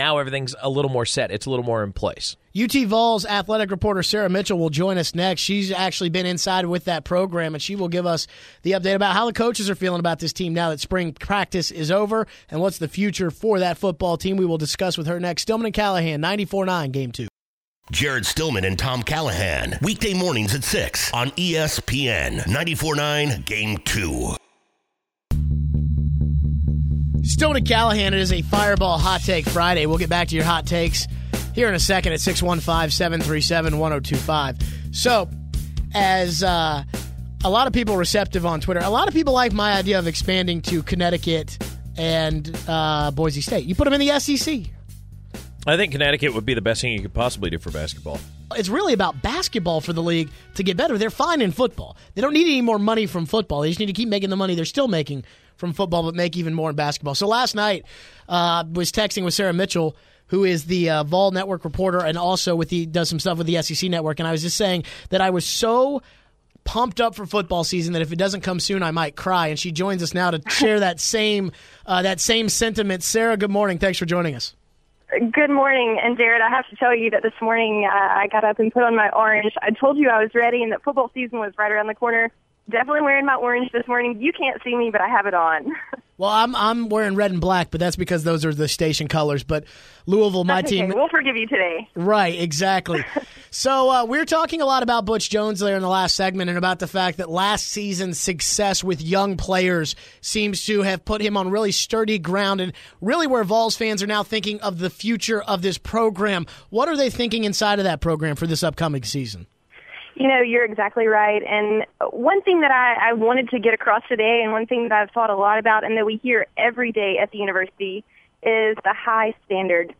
ESPN Radio Interview
This past Friday, my office venue was a patio overlooking the pool at the condo where I was vacationing in Santa Rosa Beach, Florida.
espn-the-game-interview.m4a